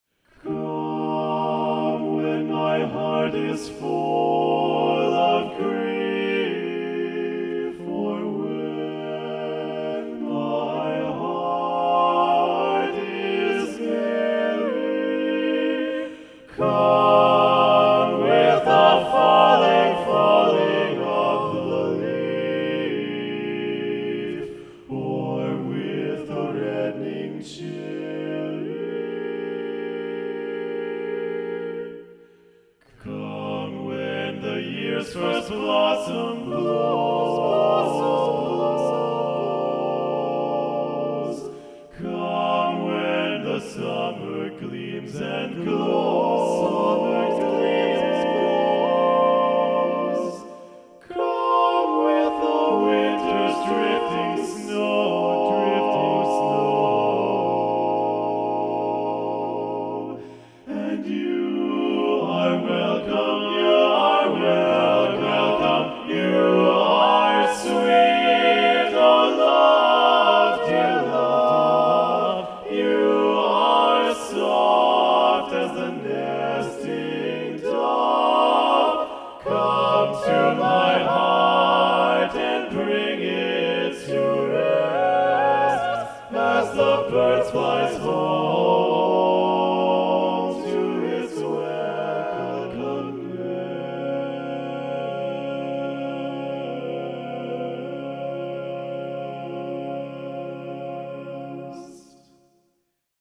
Instrumentation: SATB